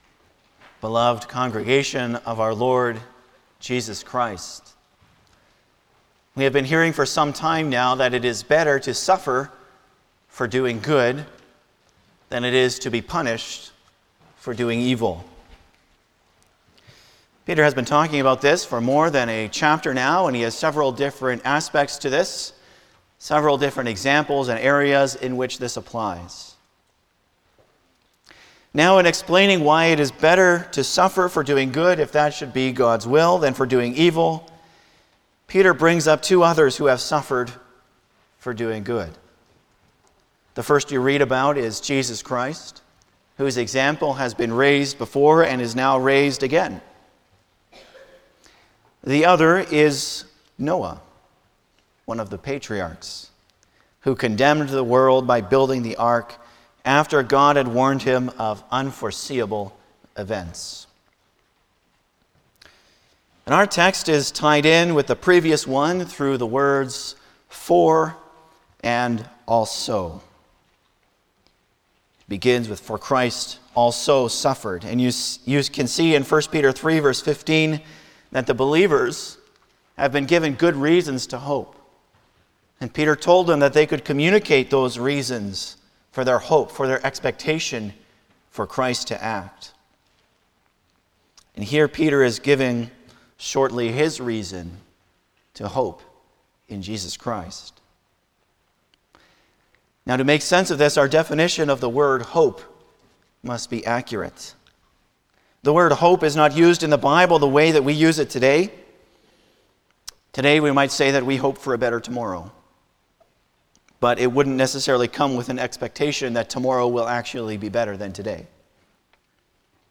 Passage: 1 Peter 3:18-22 Service Type: Sunday morning
09-Sermon.mp3